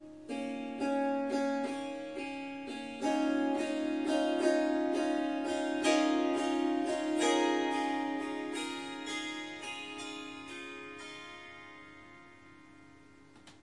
Swarmandal印度竖琴曲谱 " 竖琴轻柔的戏剧性曲谱3
这个奇妙的乐器是Swarmandal和Tampura的结合。
它被调到C sharp，但我已经将第四个音符（F sharp）从音阶中删除了。
这些片段取自三天不同的录音，因此您可能会发现音量和背景噪音略有差异。一些录音有一些环境噪音（鸟鸣，风铃）。
Tag: 竖琴 弦乐 旋律 Swarsangam 民族 Swarsangam 印度 即兴重复段 Surmandal Swarmandal 旋律